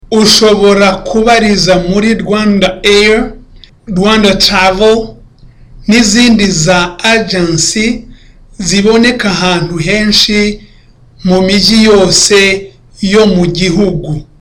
Dialogue: A conversation between Karani and Murekatete
(Smiling)